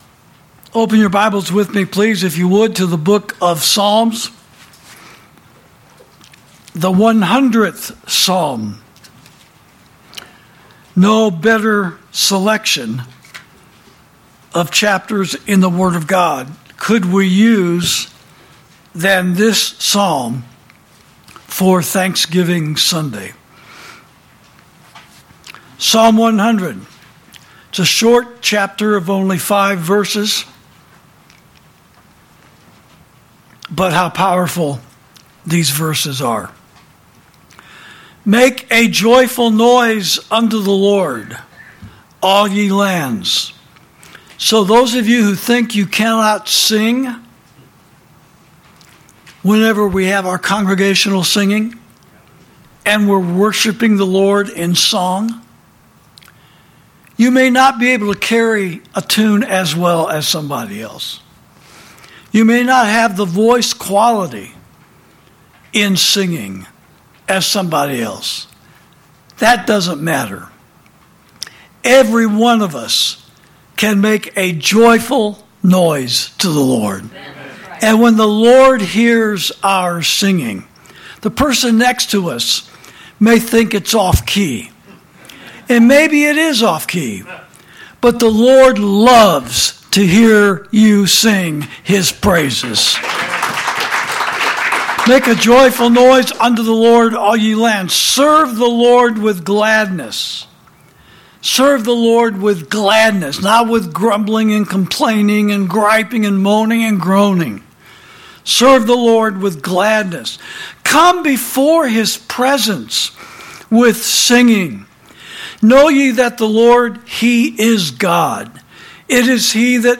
during the service at Liberty Fellowship.